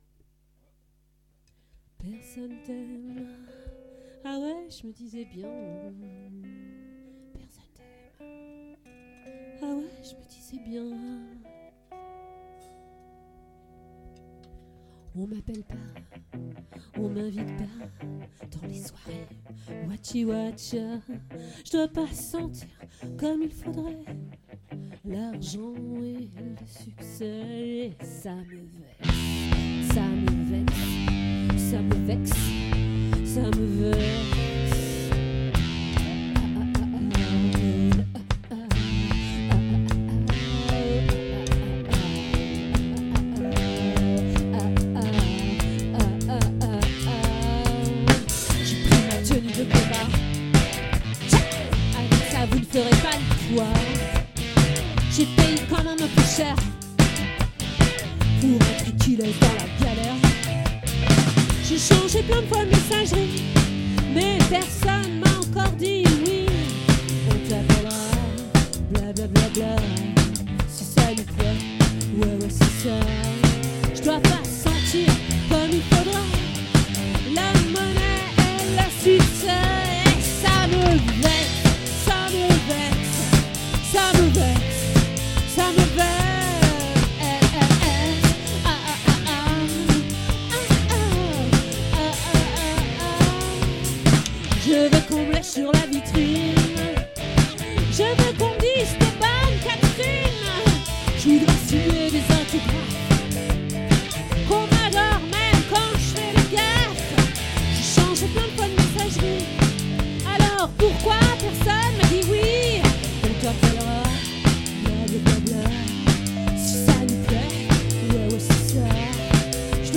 🏠 Accueil Repetitions Records_2023_06_14_OLVRE